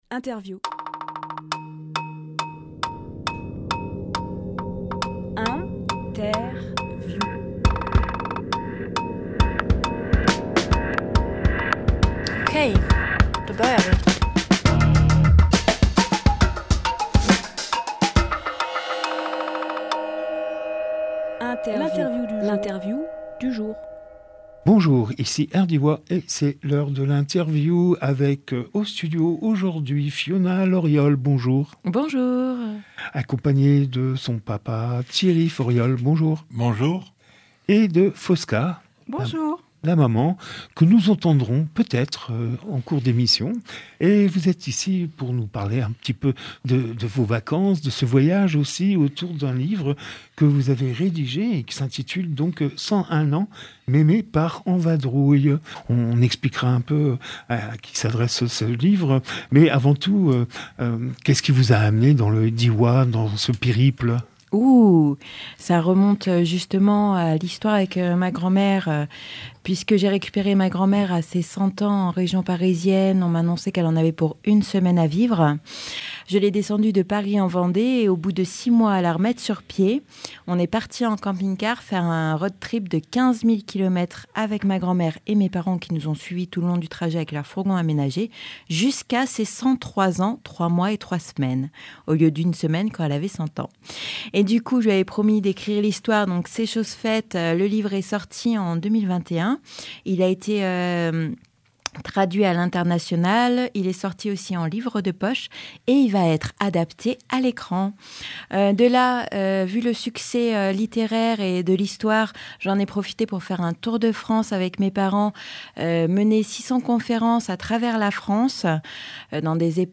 Emission - Interview 101 ans Mémé part en vadrouille Publié le 23 juillet 2024 Partager sur…
Lieu : Studio Rdwa